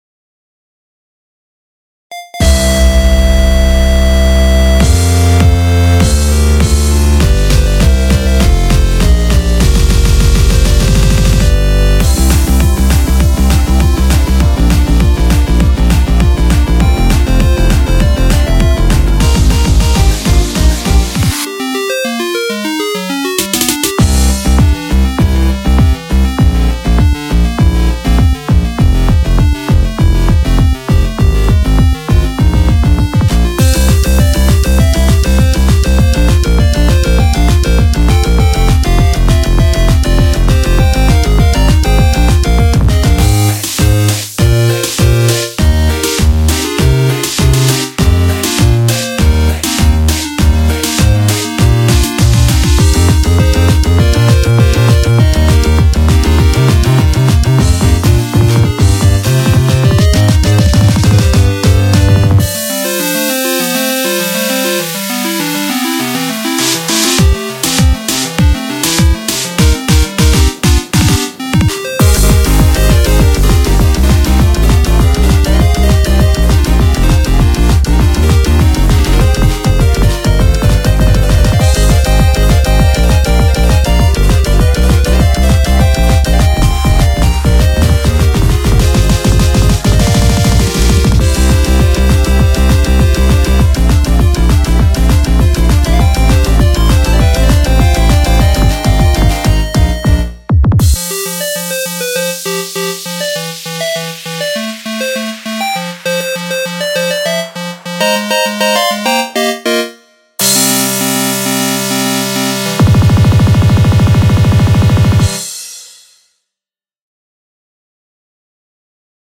BPM100-200
Audio QualityPerfect (High Quality)
FANMADE 100%